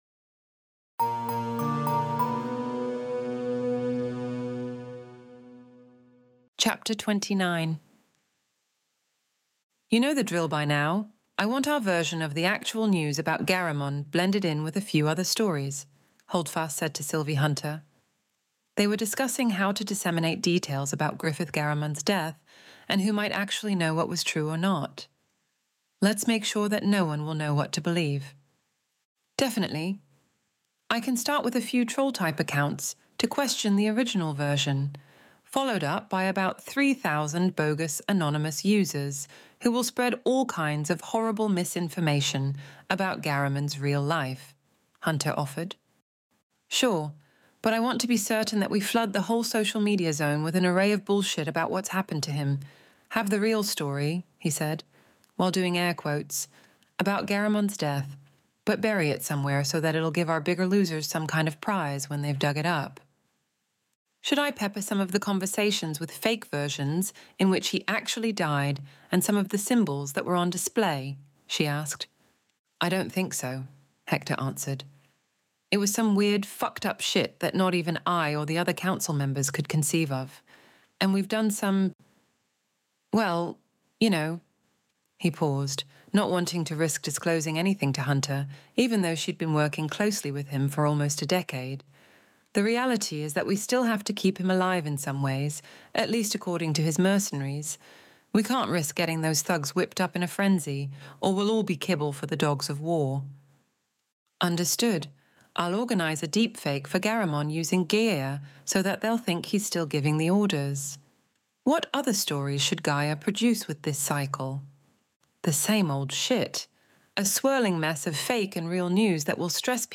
Extinction Event Audiobook Chapter 29